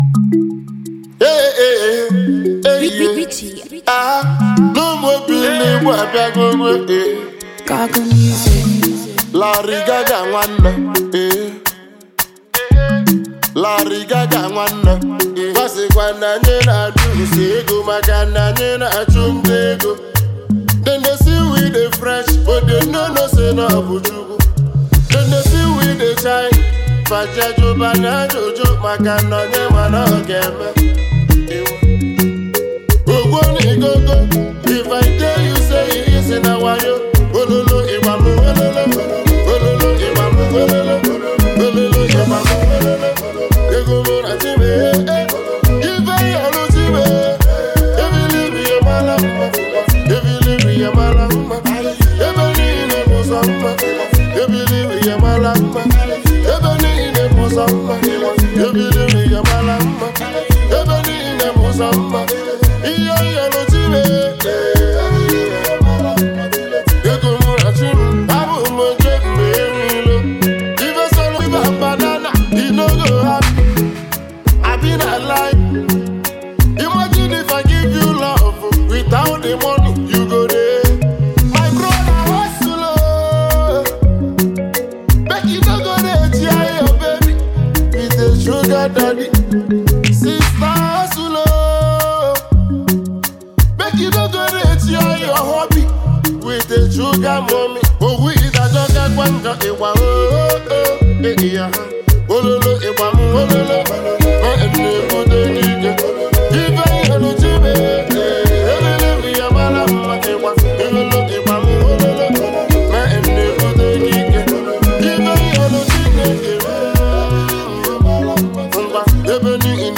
Highlife
guitar